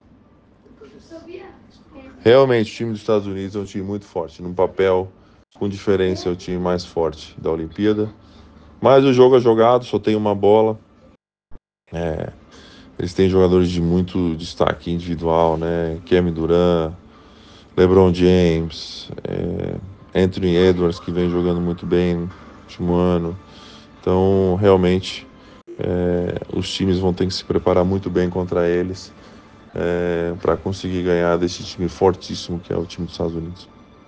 Entrevista com Tiago Splitter – Auxiliar técnico da Seleção Brasileira de Basquete